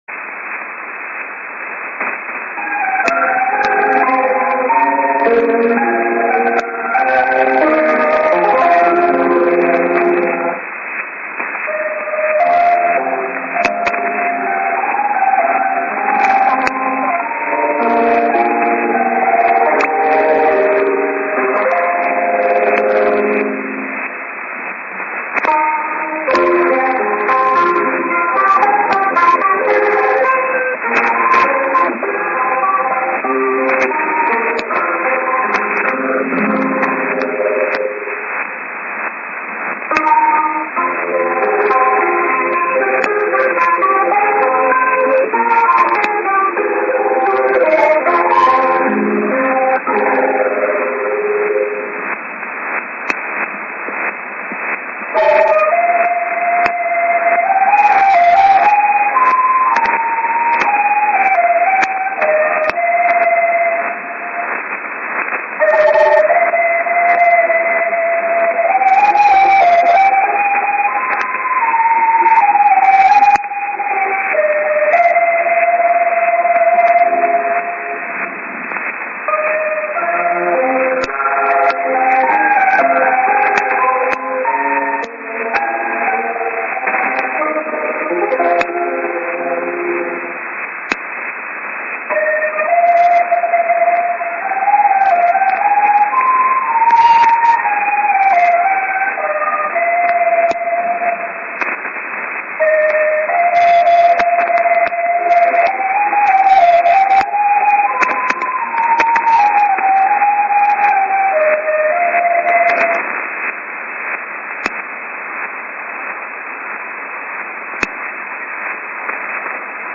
St. IS->02'20":ID(man)->ID+SKJ(man)->
04:00 は良くなかった。指向性の変わった、04:30 開始前のＩＳは良好だが番組からは弱くなったり強くなったり。05:00 も開始前のＩＳは良好だが、番組は弱くＮＧで弱いまま 05:30 の終了を迎えた。